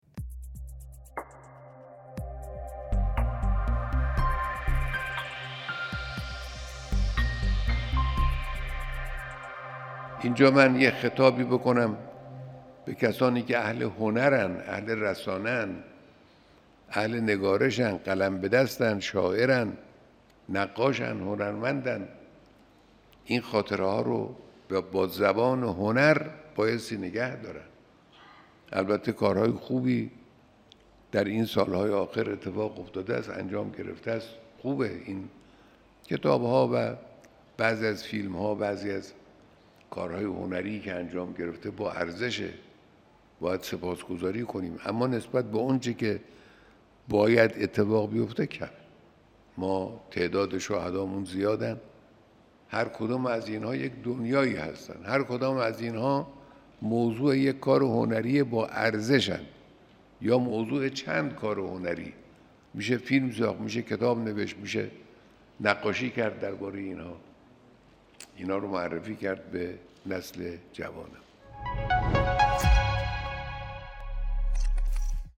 سخنرانی رهبر معظم انقلاب در دیدار خانواده‌های شهدا
حضرت آیت‌الله خامنه‌ای، رهبر انقلاب اسلامی صبح امروز در دیدار با خانواده‌های شهدا از اهمیت حفظ یاد شهیدان با زبان هنر سخن گفتند.